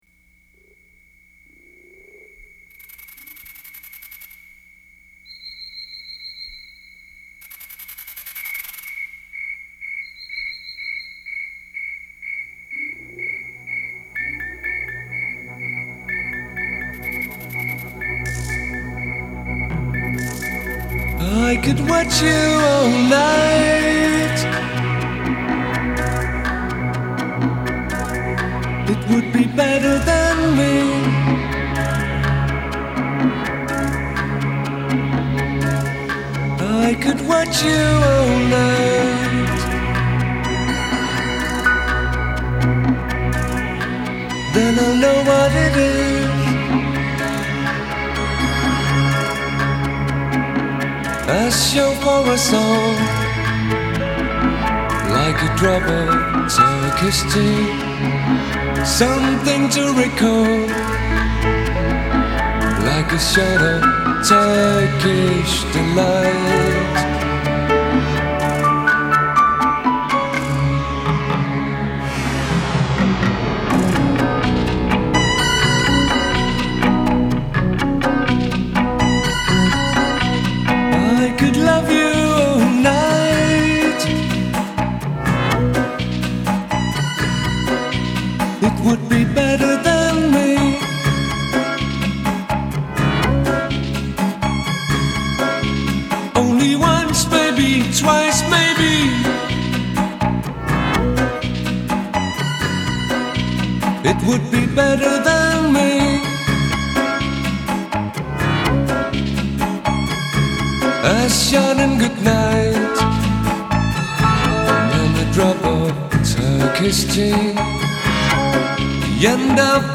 Genre: Pop, Rock.